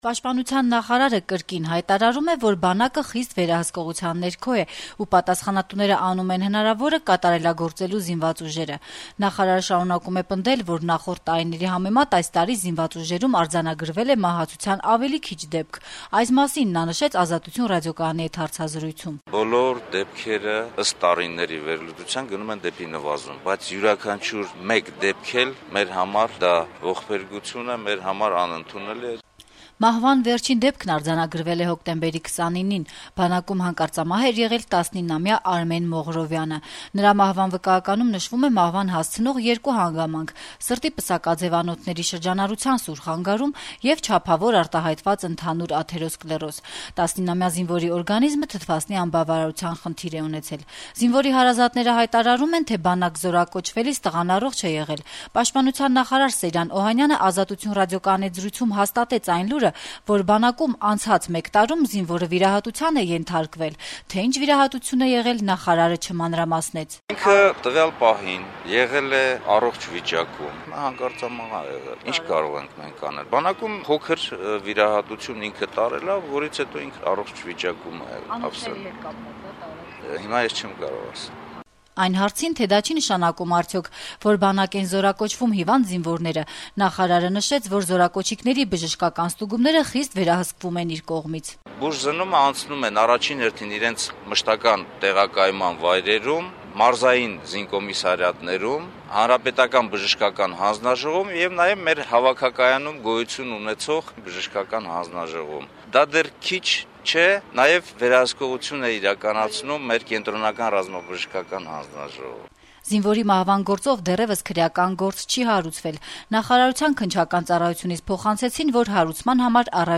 Սեյրան Օհանյանը «Ազատություն» ռադիոկայանի տված հարցազրույցում ասաց` բանակը խիստ վերահսկողության ներքո է: